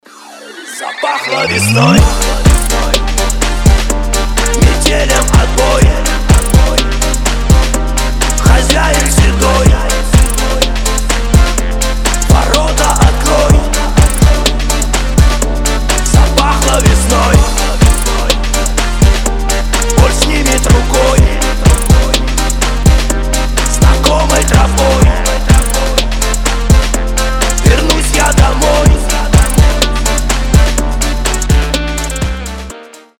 • Качество: 320, Stereo
блатные
ремиксы
фонк